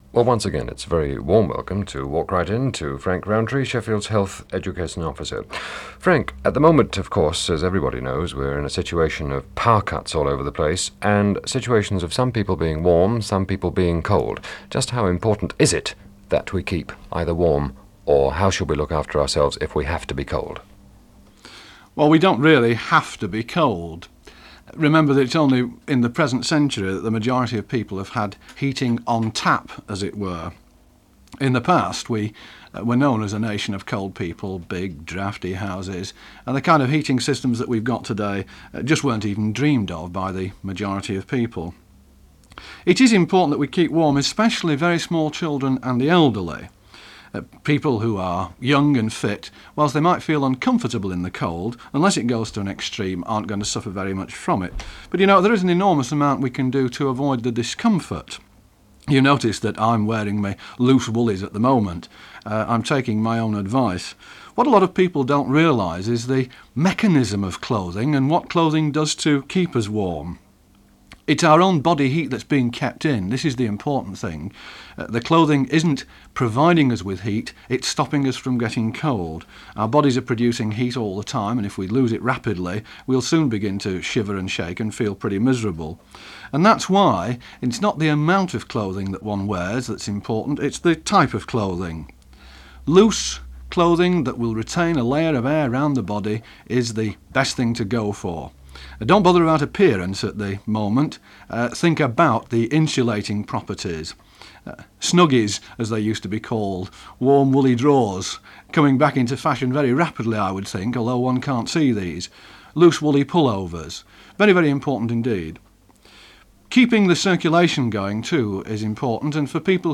Sheffield : BBC Radio Sheffield, 1972.